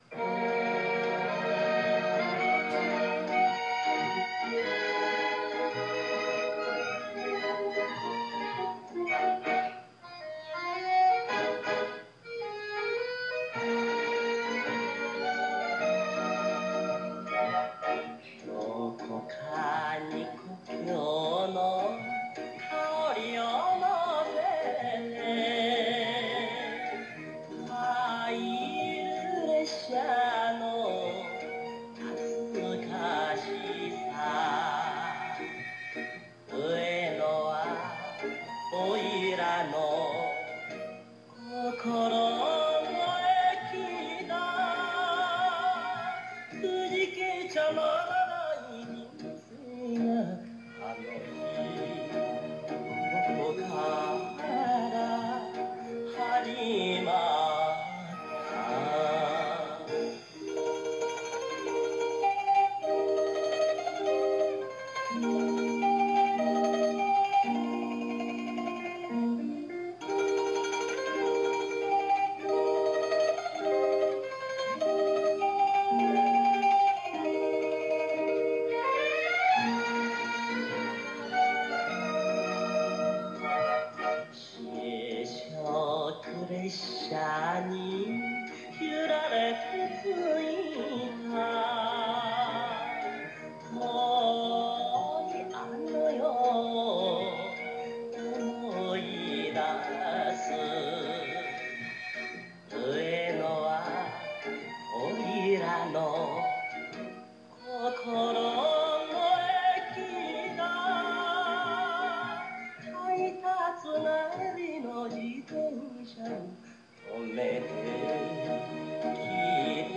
Enka